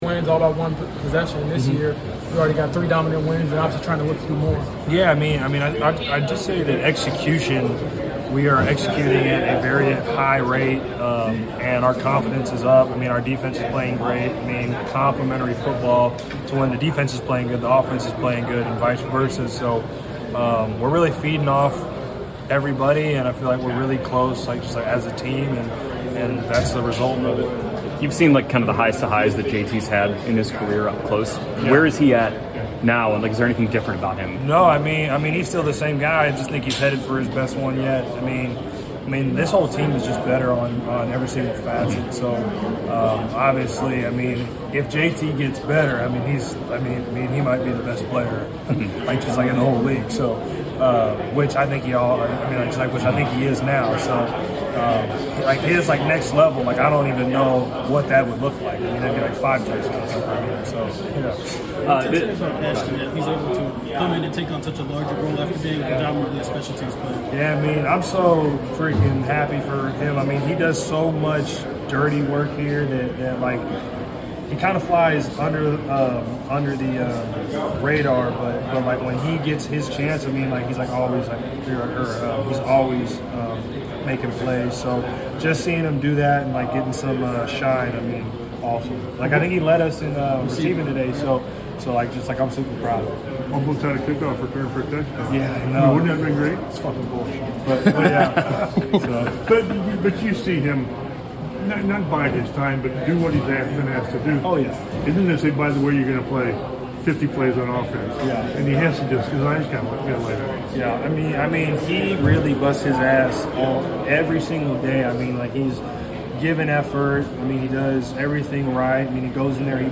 Indianapolis Colts Wide Receiver Michael Pittman Jr. Postgame Interview after defeating the Las Vegas Raiders at Lucas Oil Stadium.